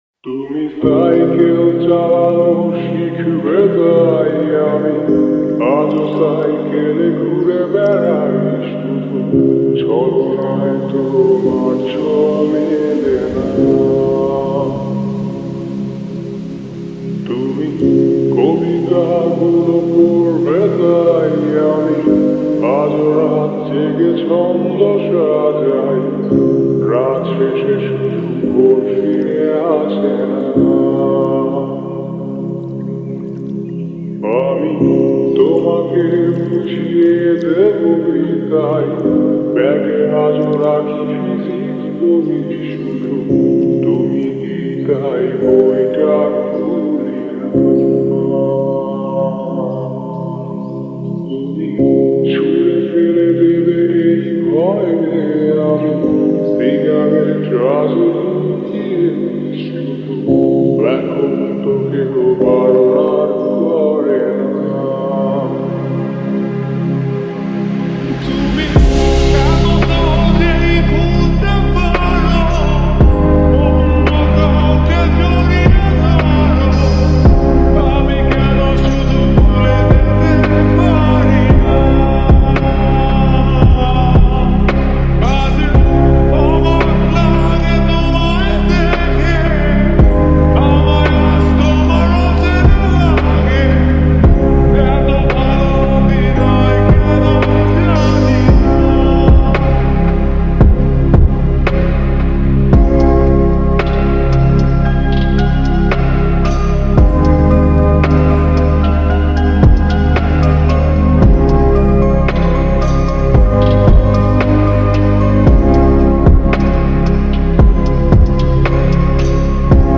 Slowed And Reverb Bangla New Lofi Song